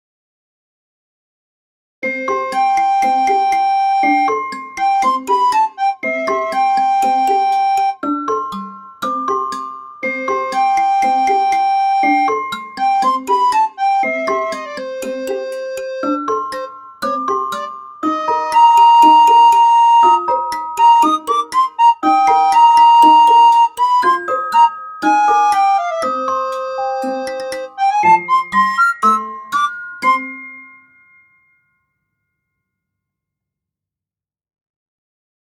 可愛い雰囲気の曲です。
簡素です
タグ かわいい